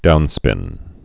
(dounspĭn)